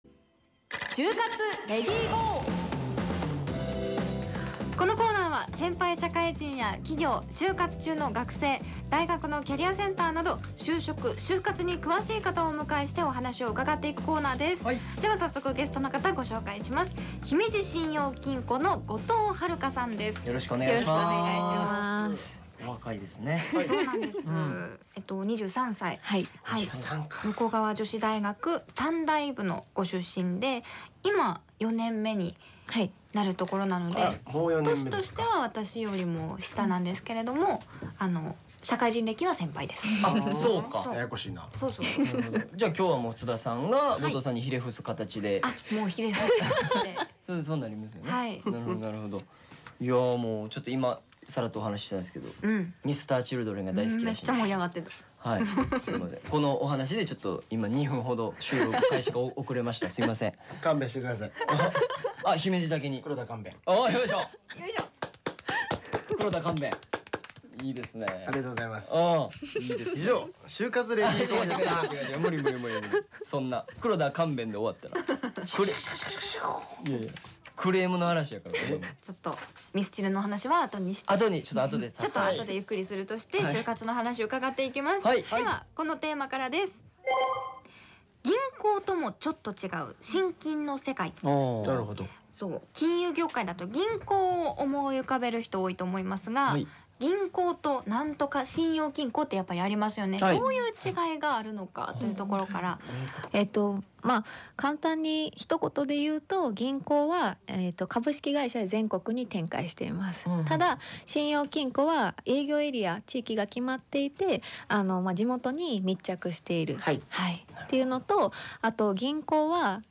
『ネイビーズアフロのレディGO！HYOGO』2020年9月18日放送回（「就活レディGO！」音声）